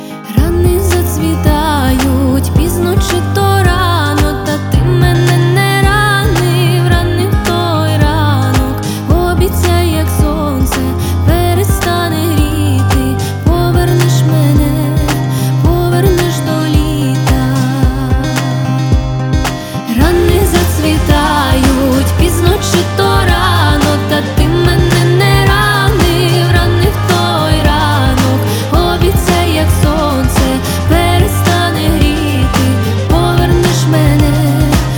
Жанр: Поп музыка / Украинские